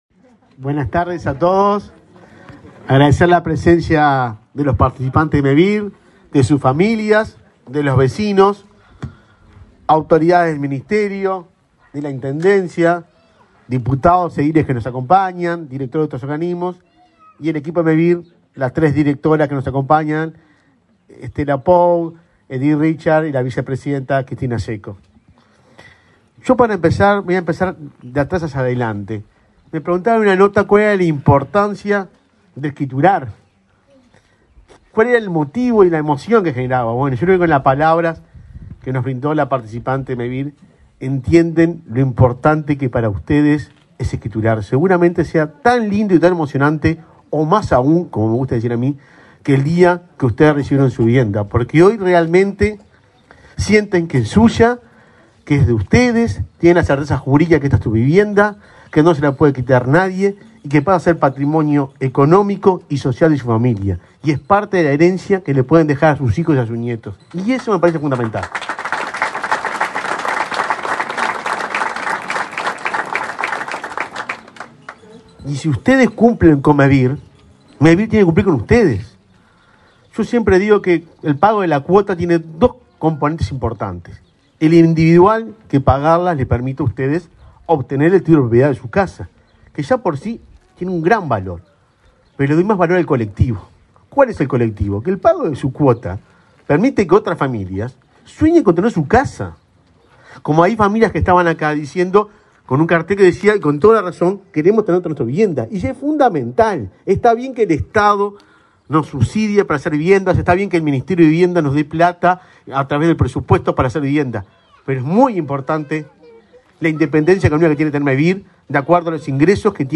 Conferencia de prensa de Mevir por escritura de viviendas en Puntas de Valdez
Participaron del evento el ministro interino de Vivienda, Tabaré Hackenbruch, y el presidente de Mevir, Juan Pablo Delgado.